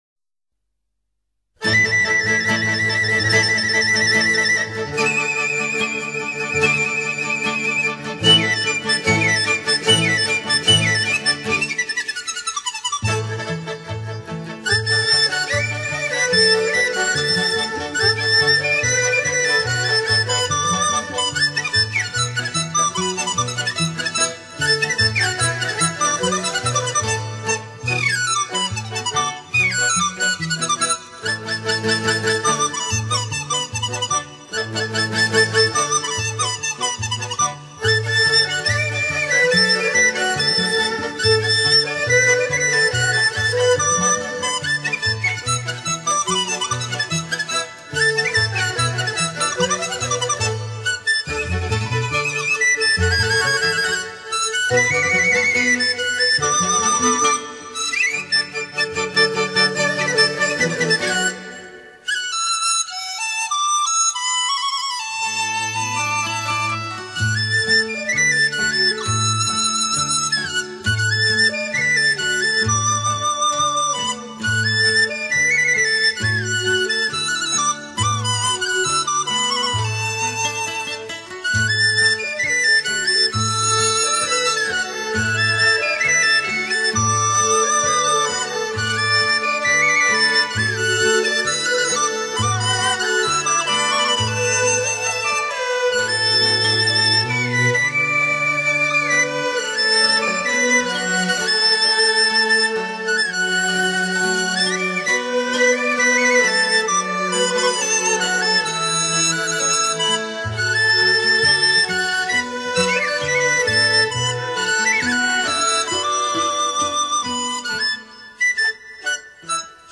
中国竹笛的种类及名称极为丰富多彩，具有强烈的民族特色，发音动人、婉回。